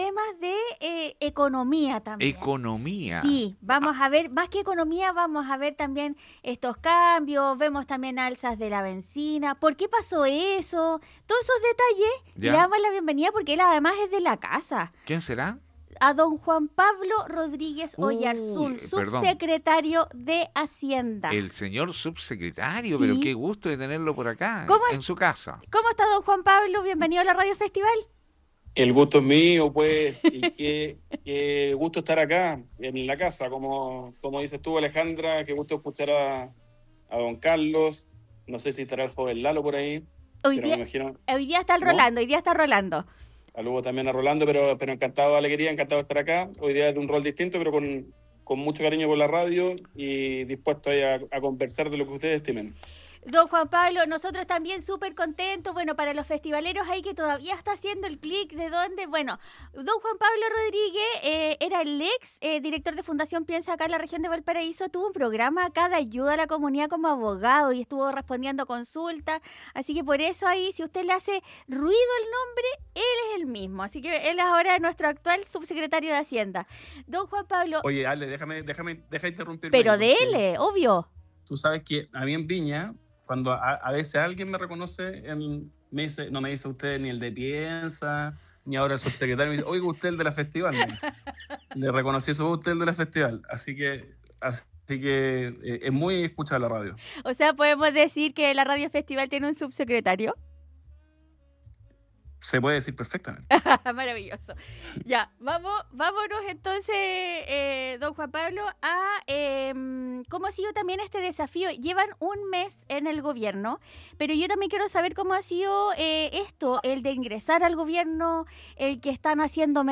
Subsecretario de Hacienda Juan Pablo Rodriguez en Radio Festival